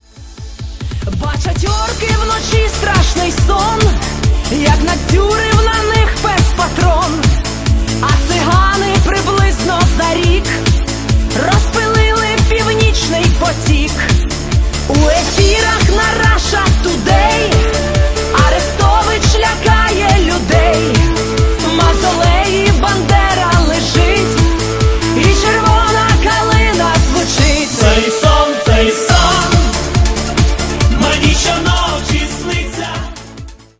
• Качество: 320, Stereo
дуэт
Отрывок украинской песни